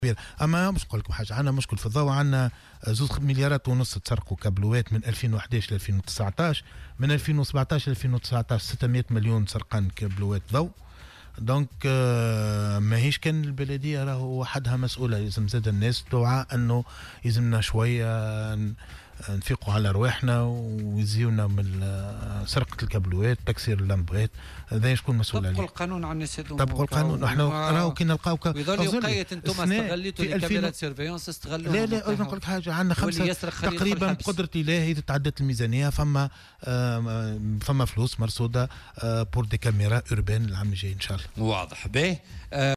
وأكد ضيف "بوليتيكا" على "الجوهرة اف أم" أنه تم رصد ميزانية خاصة لهذا المشروع الذي سيدخل حيز التنفيذ قريبا بعد المصادقة على الميزانية.